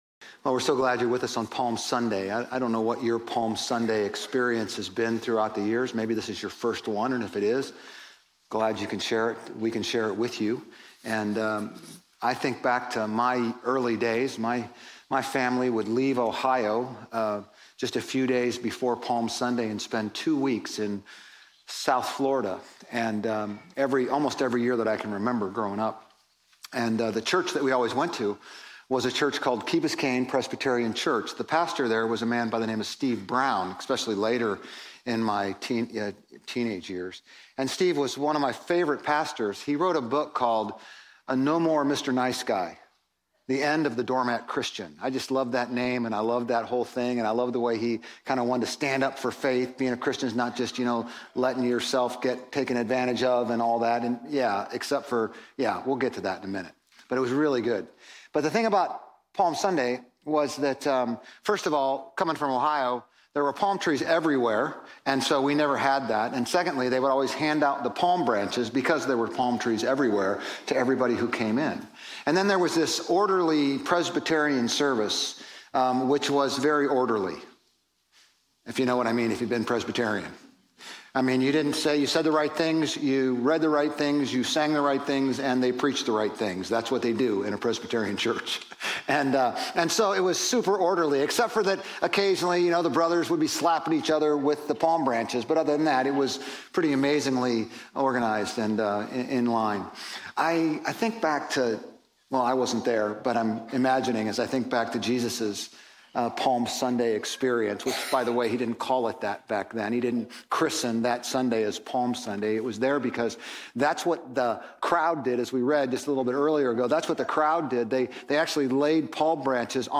Grace Community Church Old Jacksonville Campus Sermons 4_13 Old Jacksonville Campus Apr 14 2025 | 00:34:26 1x 00:00 / 00:34:26 Subscribe Share RSS Feed Share Link Embed